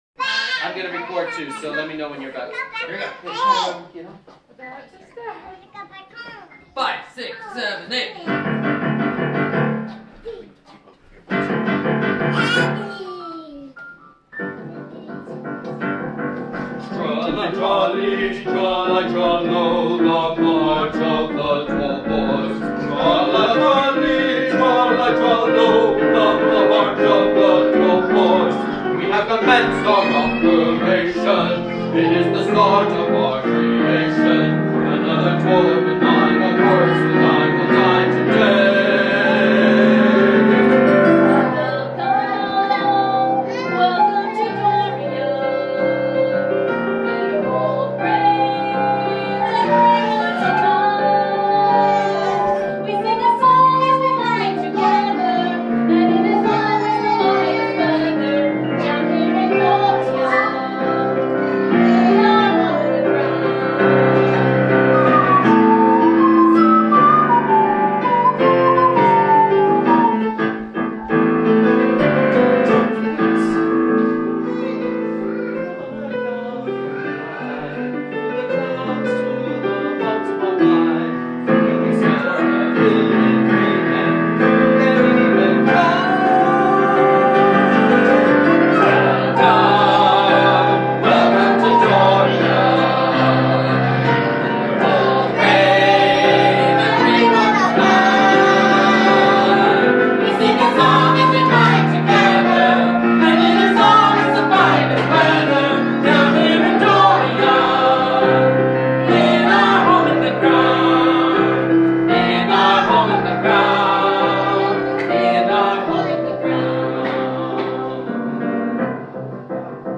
Vocal solo, violin, flute (ocarina), and piano duet
Song-of-Doria-Live-Audio.mp3